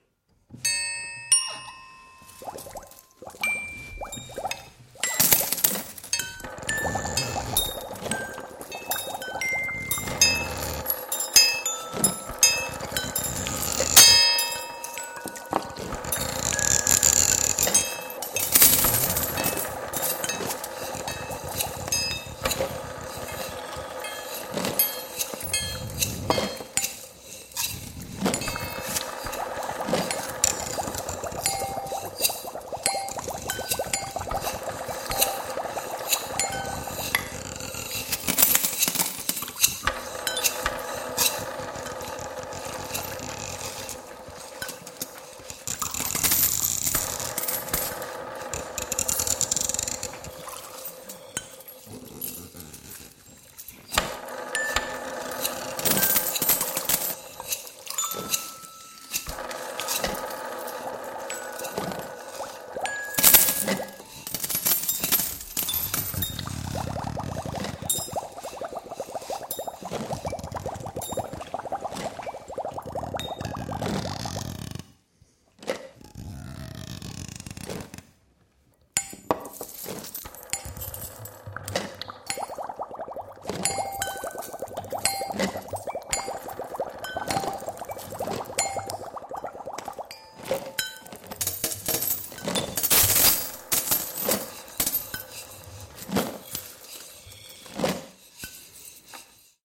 Звуки лаборатории
Здесь собраны реалистичные аудиоэффекты: от тихого гудения оборудования до звонких перекликов стеклянных колб.